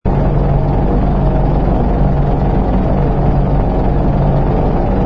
engine_bw_freighter_loop.wav